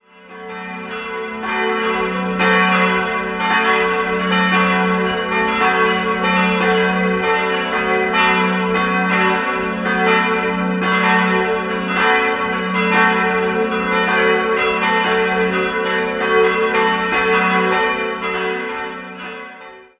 4-stimmiges ausgefülltes Fis-Moll-Geläute: fis'-a'-h'-cis'' Die drei größeren Glocken wurden 1952 von Hans Hüesker in der Gießerei Petit&Edelbrock in Gescher gegossen.